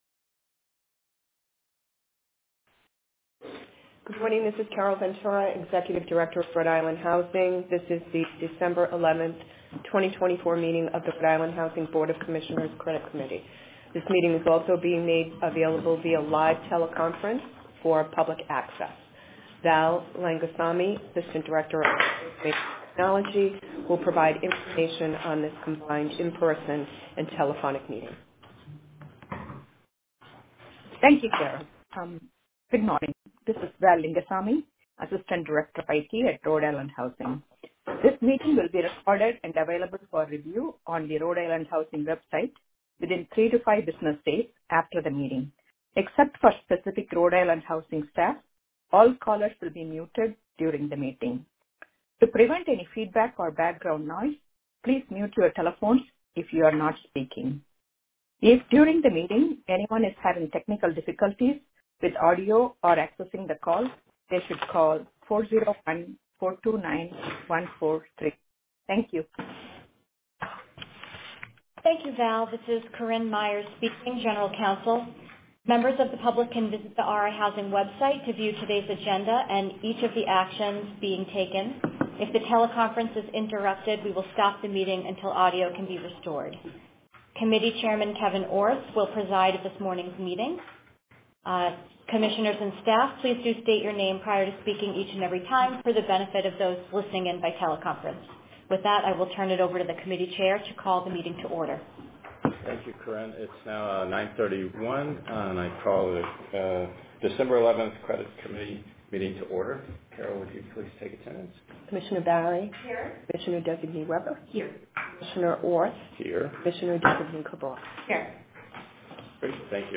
Credit Committee Meeting: December 11, 2024